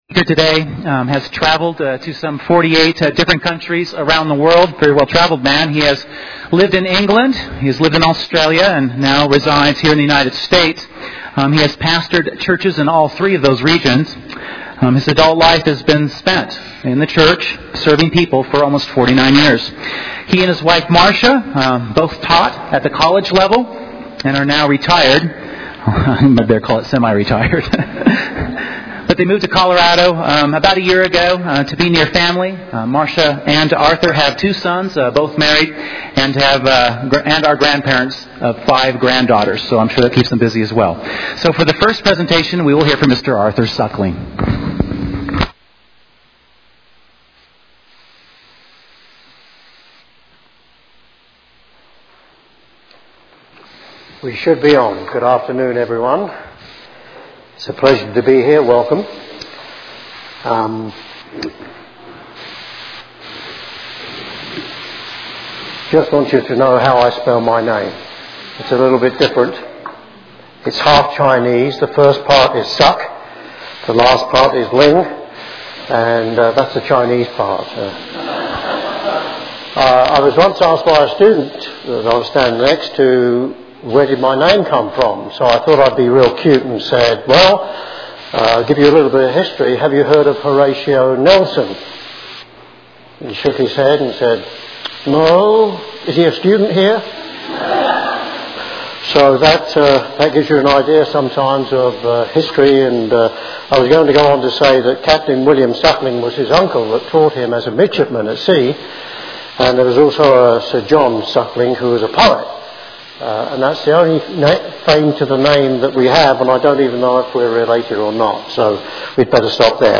Our challenge is to live now in the terms and conditions of that coming Kingdom. Learn more in part 1 of this Kingdom of God seminar.